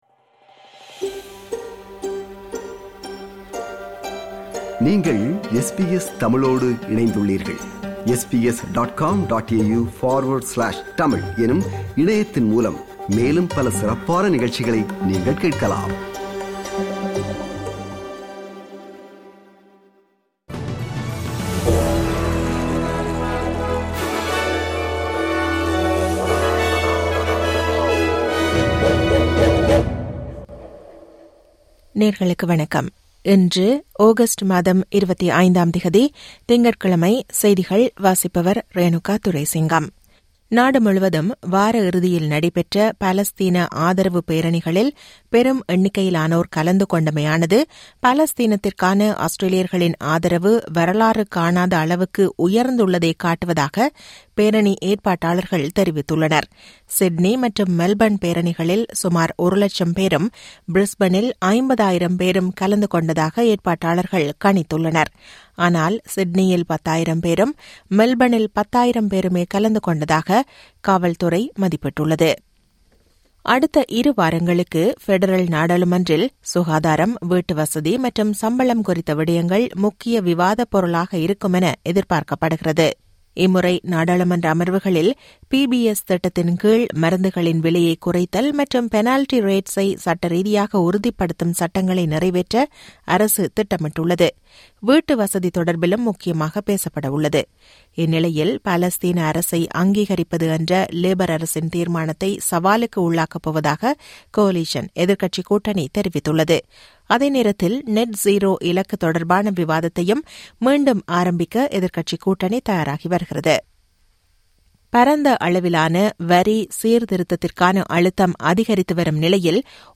SBS தமிழ் ஒலிபரப்பின் இன்றைய (திங்கட்கிழமை 25/08/2025) செய்திகள்.